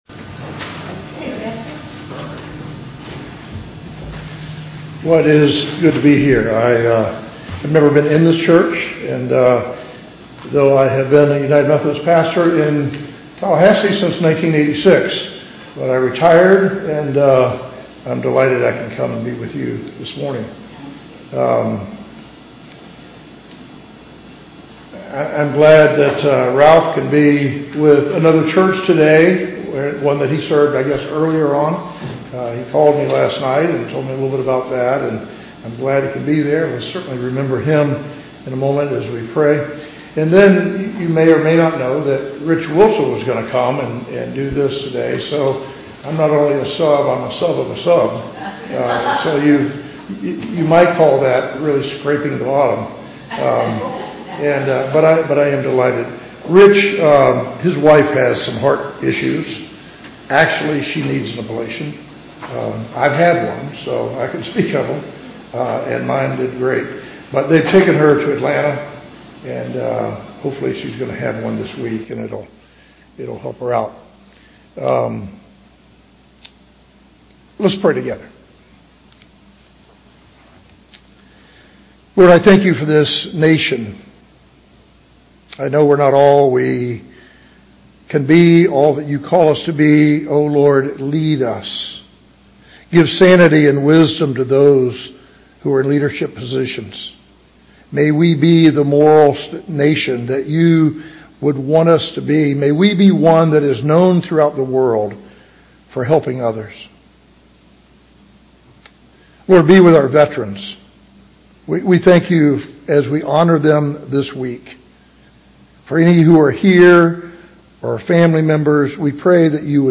Bethel Church Service
The Message